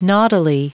Prononciation du mot naughtily en anglais (fichier audio)
Prononciation du mot : naughtily